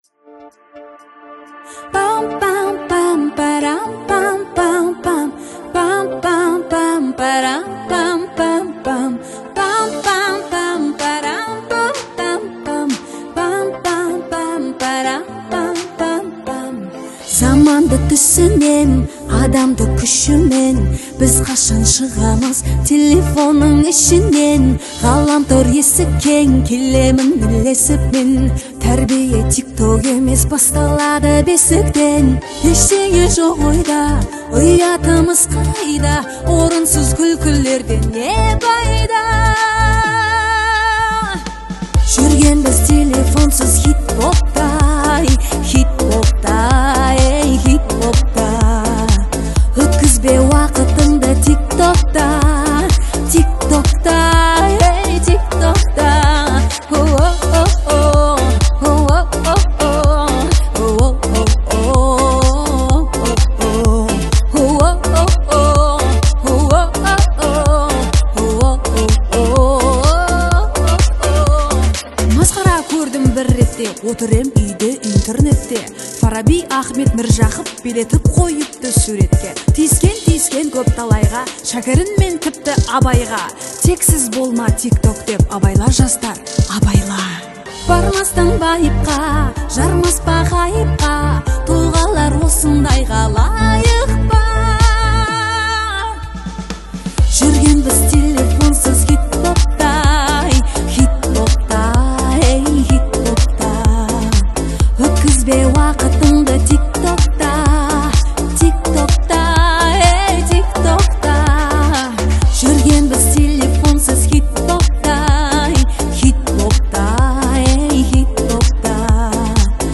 яркими синтезаторами и запоминающимся ритмом
демонстрирует уверенное и харизматичное исполнение